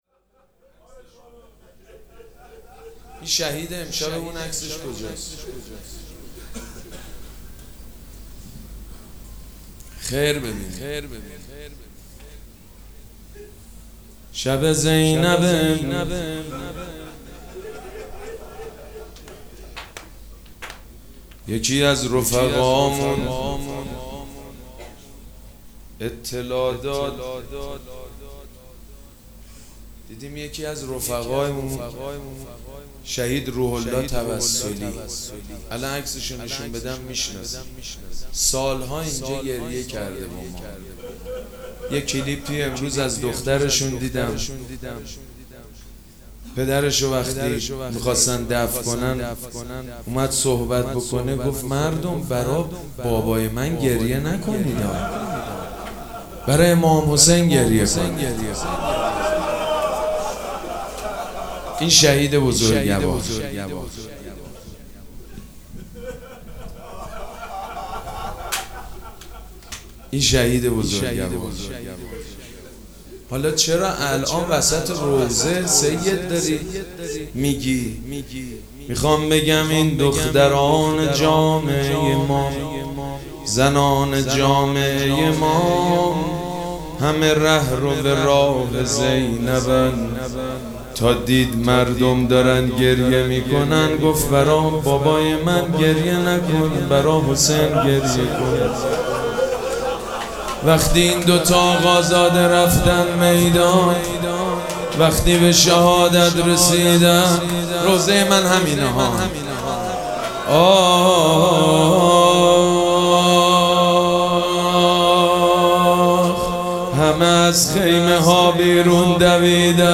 مراسم عزاداری شب چهارم محرم الحرام ۱۴۴۷
روضه
مداح